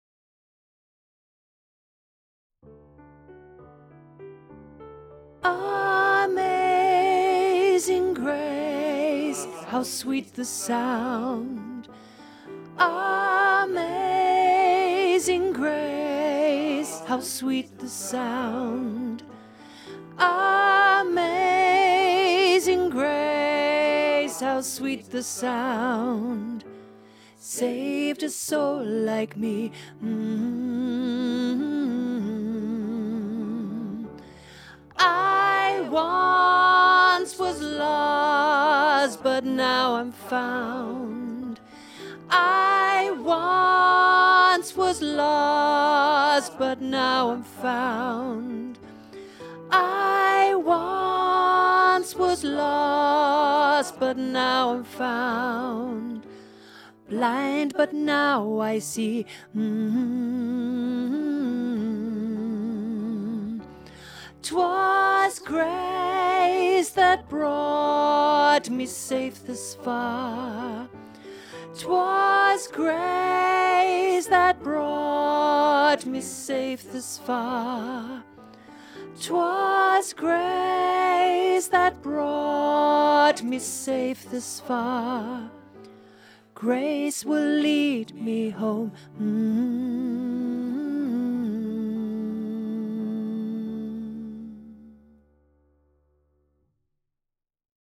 Genre: Choral.